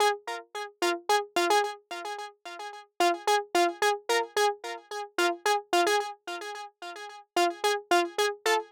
03 Solo Synth PT3.wav